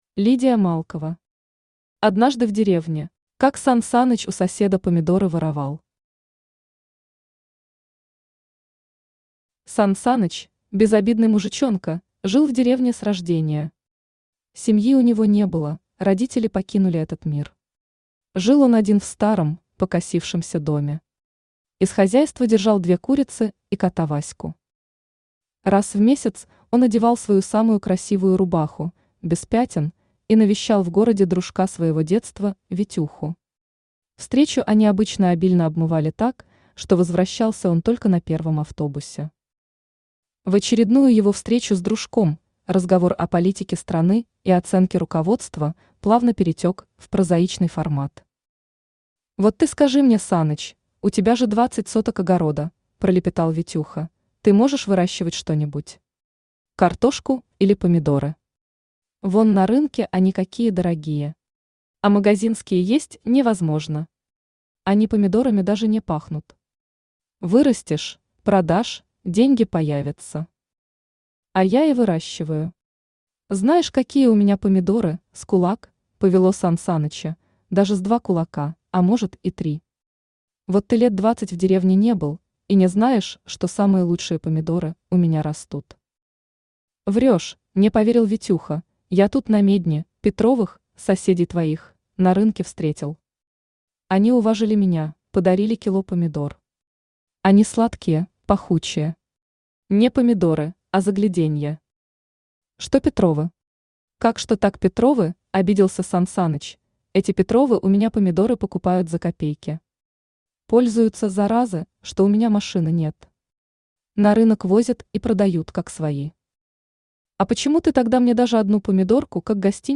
Автор Лидия Малкова Читает аудиокнигу Авточтец ЛитРес.